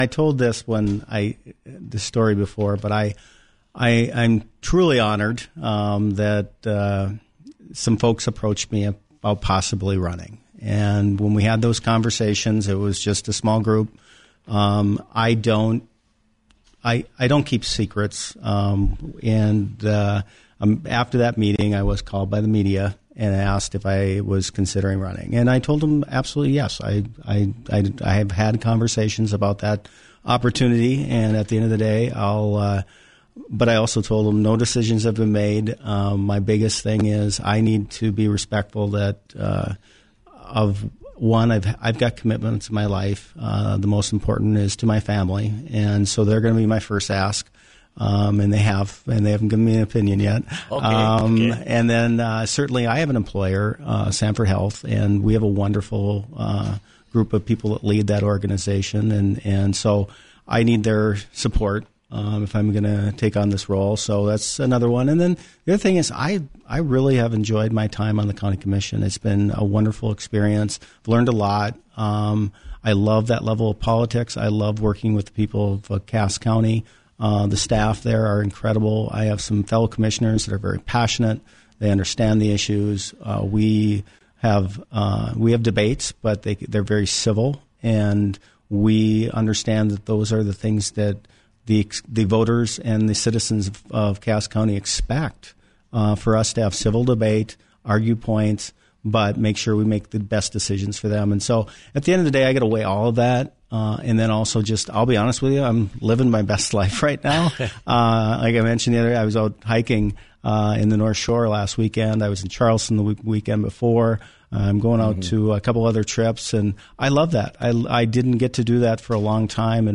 Listen:  Cass County Commissioner Joel Vettel on WDAY Radio